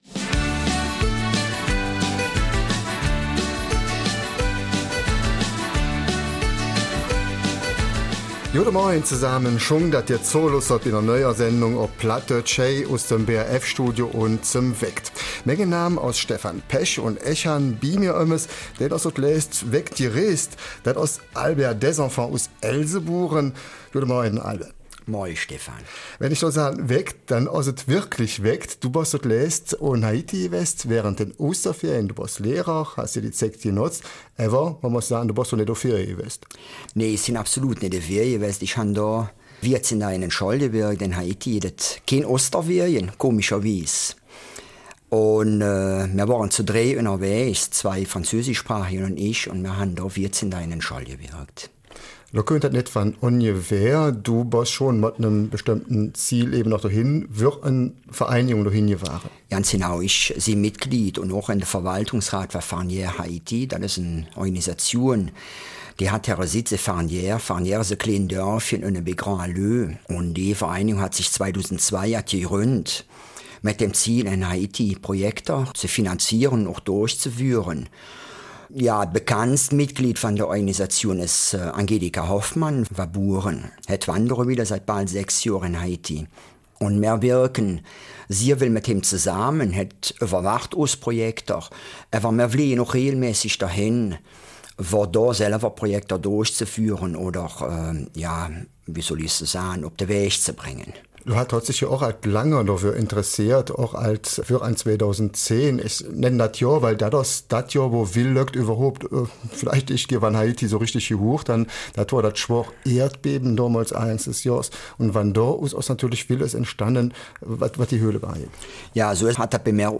Eifeler Mundart: Haiti braucht Hilfe zur Selbsthilfe